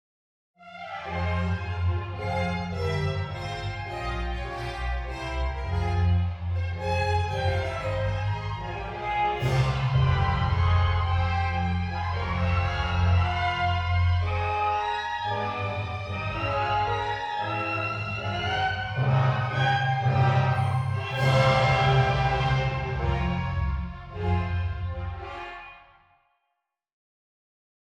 Section VI - C2 Stereo Auralization of 24 Channel Loudspeaker Orchestra
StereoAuralization.wav